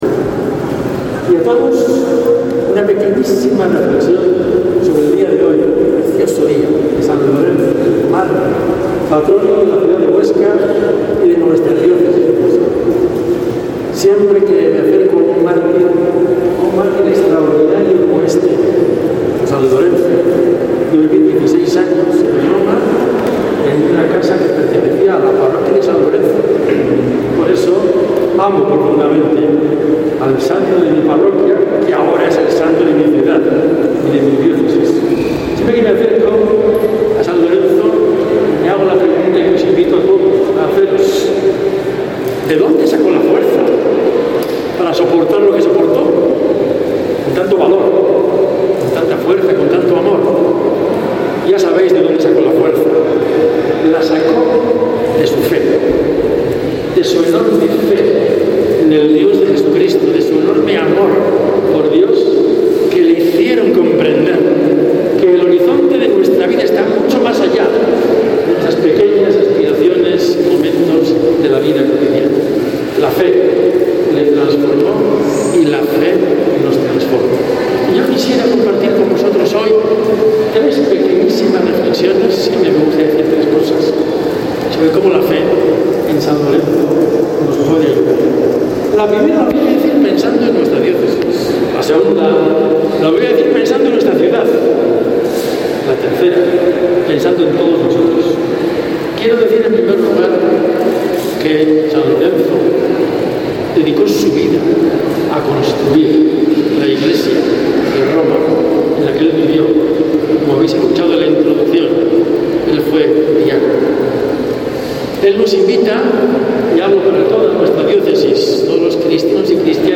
La procesión ha sido el acto central de una mañana calurosa que ha comenzado y concluido en la basílica de san Lorenzo con la solemne misa pontifical que ha estado presidida por nuestro obispo, el padre Pedro Aguado Cuesta.
Homilia-10-de-agosto-audio.mp3